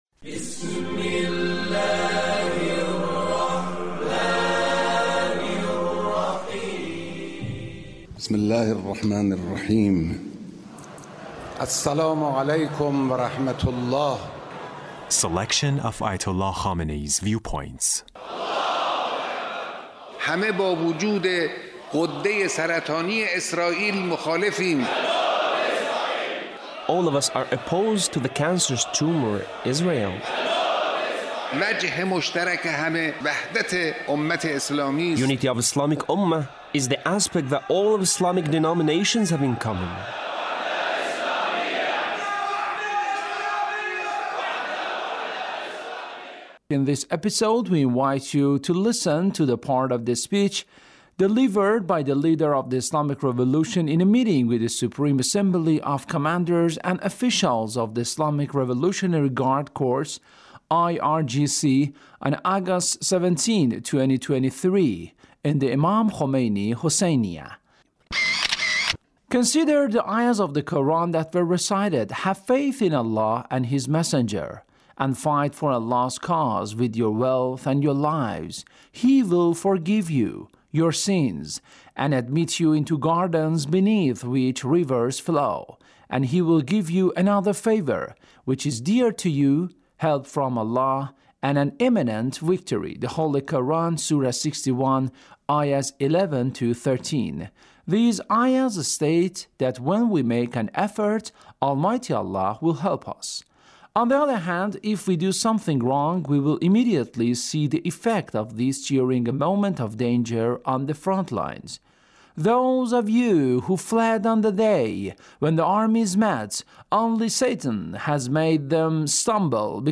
Leader's Speech (1818)